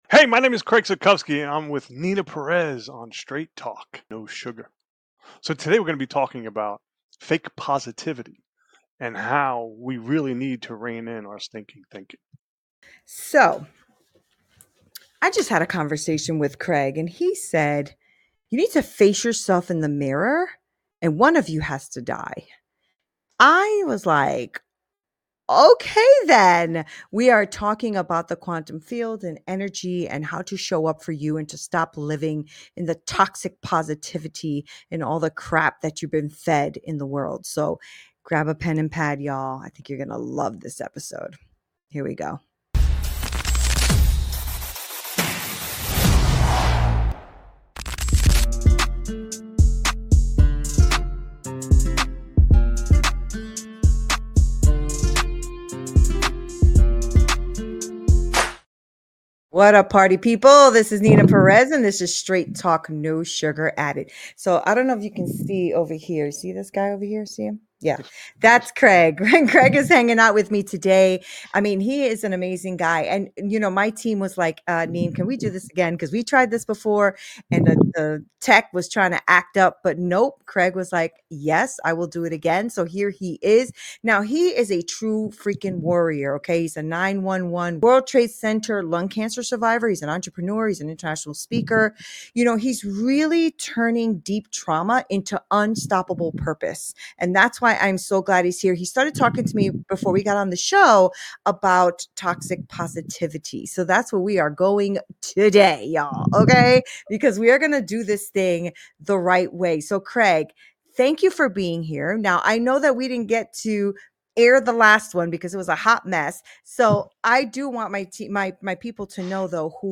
🛑💭💥 💬 Join us for this inspiring convo filled with raw truths, actionable steps, and a whole lot of energy!